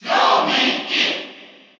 Category: Crowd cheers (SSBU) You cannot overwrite this file.
Dark_Pit_Cheer_Russian_SSBU.ogg